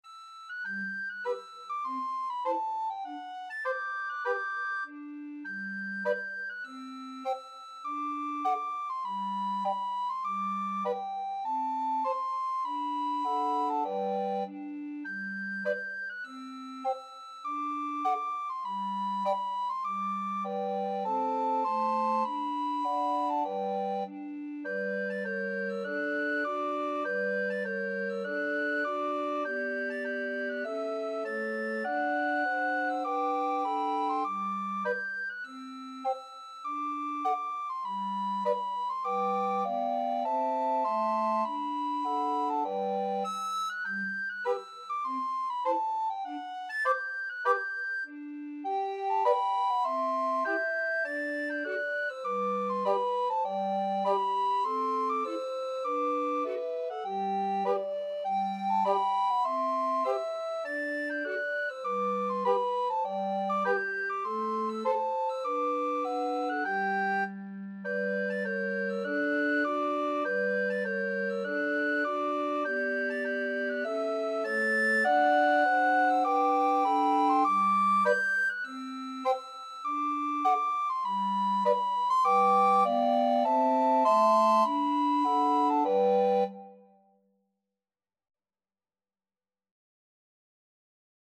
Soprano RecorderAlto RecorderTenor RecorderBass Recorder
4/4 (View more 4/4 Music)
G major (Sounding Pitch) (View more G major Music for Recorder Quartet )
Recorder Quartet  (View more Easy Recorder Quartet Music)
Traditional (View more Traditional Recorder Quartet Music)